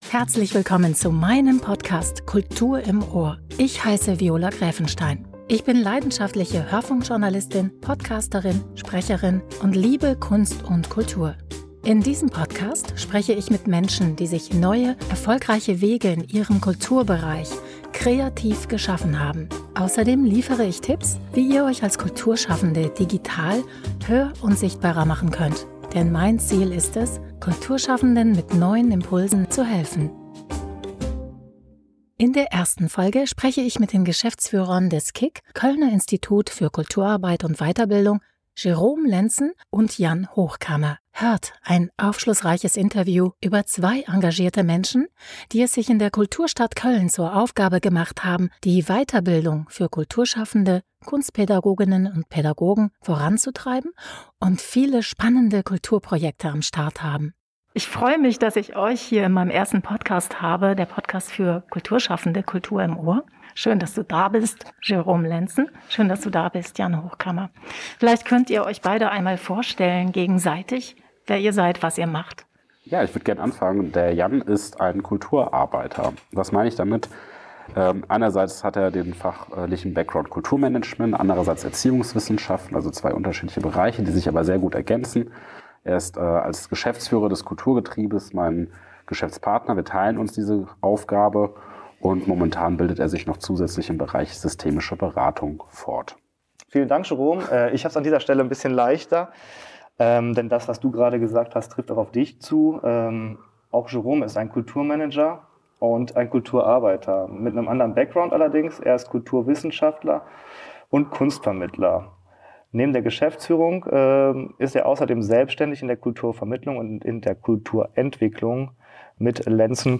aufschlussreichen Interviews